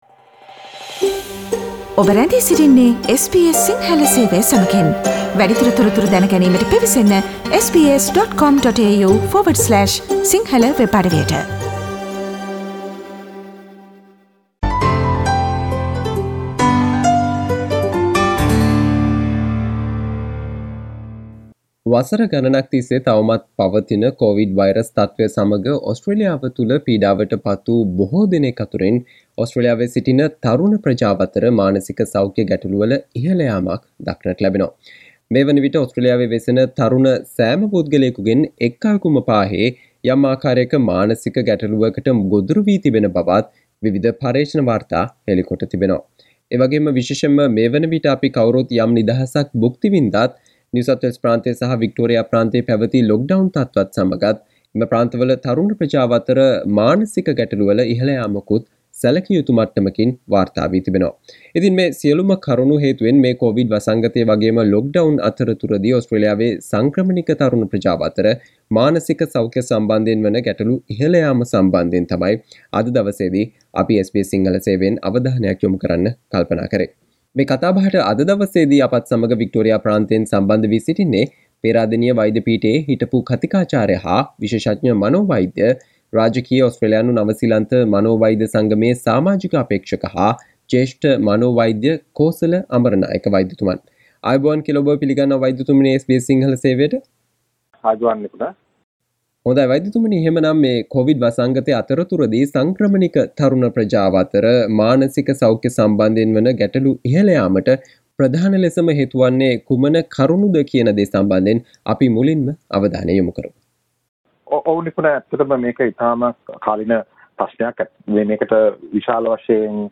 කෝවිඩ් සහ lockdown අතරතුර ඕස්ට්‍රේලියාවේ සිටින සංක්‍රමණික තරුණ ප්‍රජාව අතර මානසික සෞක්‍යය සම්බන්ධයෙන් වන ගැටලු ඉහල යාම පිළිබඳව SBS සිංහල සේවය සිදු කල සාකච්චාවට සවන්දෙන්න